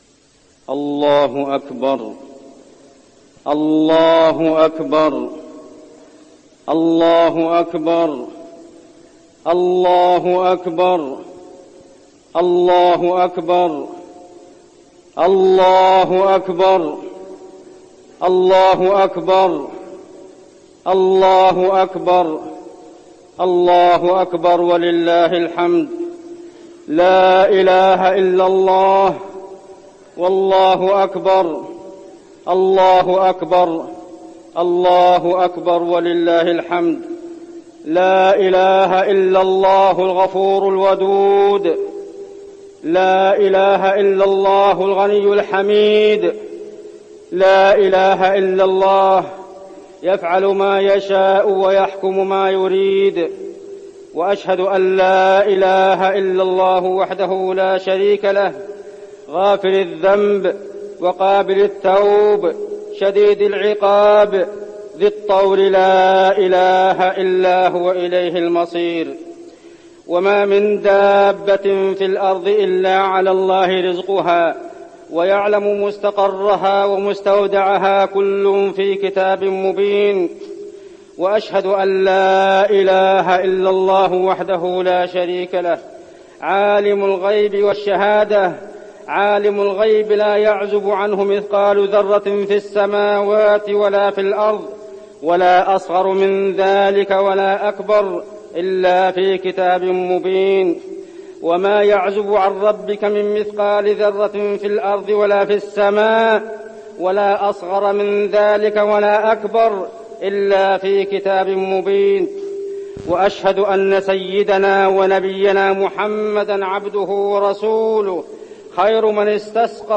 خطبة الاستسقاء - المدينة- الشيخ عبدالله الزاحم
تاريخ النشر ٢٠ ربيع الأول ١٤٠٦ هـ المكان: المسجد النبوي الشيخ: عبدالله بن محمد الزاحم عبدالله بن محمد الزاحم خطبة الاستسقاء - المدينة- الشيخ عبدالله الزاحم